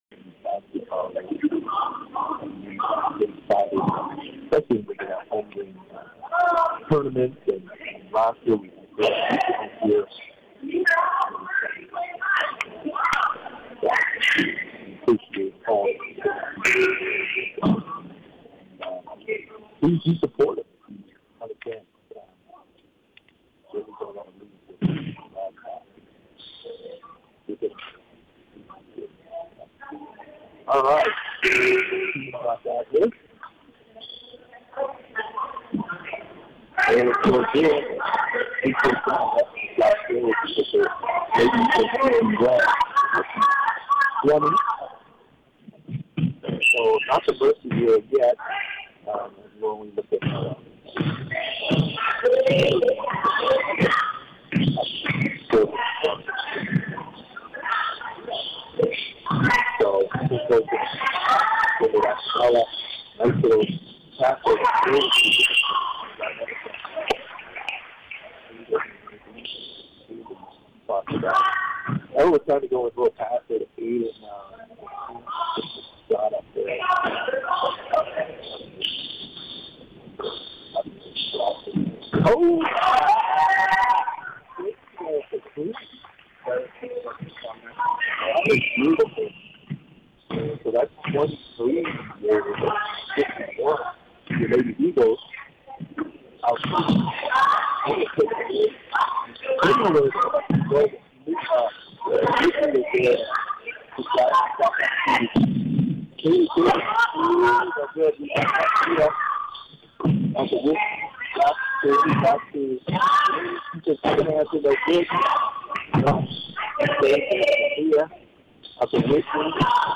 from the King Cove Auditorium